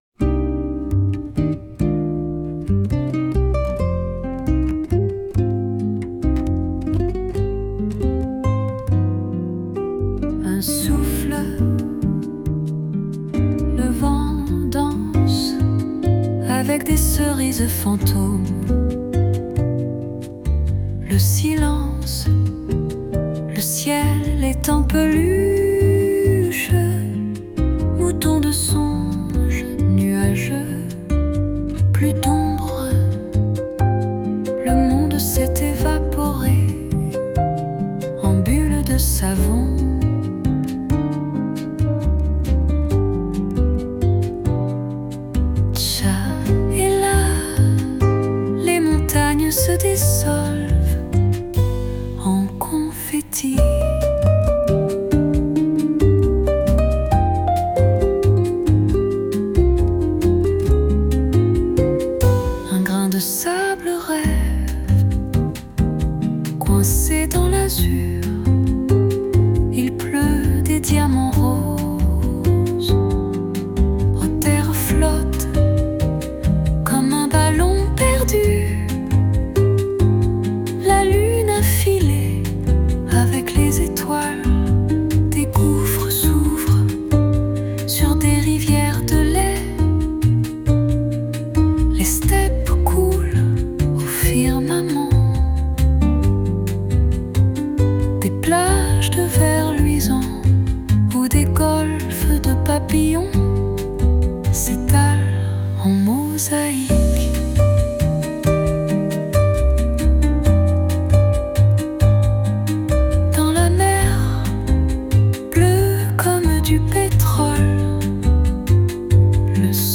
J' ai réussi à transcrire les paroles en musique via IA 👍